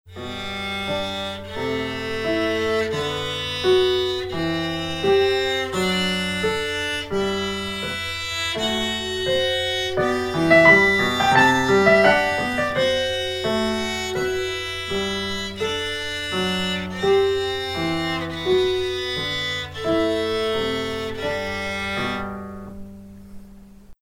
Kleines Übungsstück 3 in G-Dur für Violine
Digitalpiano Casio CDP-130
Yamaha Silent Violin SV150 mit Evah Pirazzi Gold